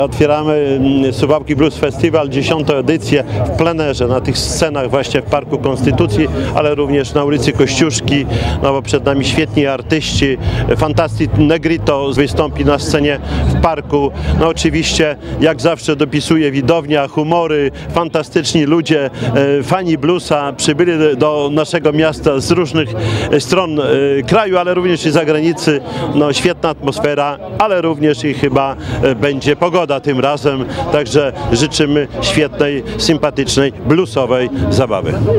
W piątkowy wieczór otwarcia festiwalu na plenerowej scenie dokonał Czesław Renkiewicz, prezydent Suwałk.